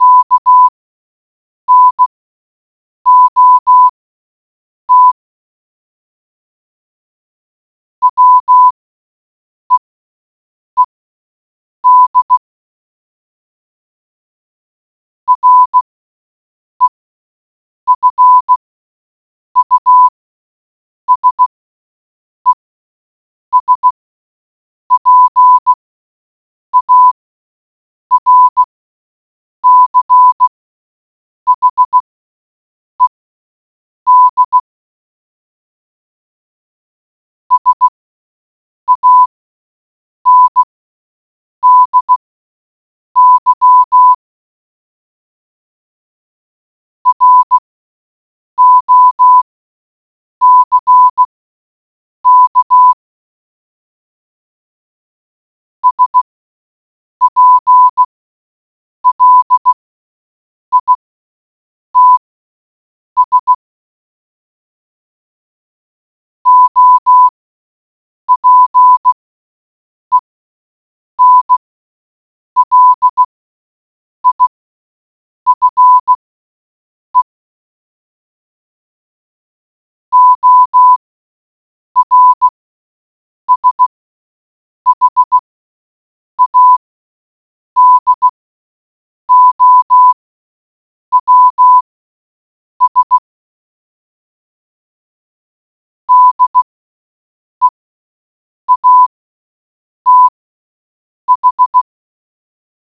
morsecode.wav